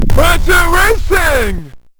-The way the guy screams "Virtua Racing" in this proto is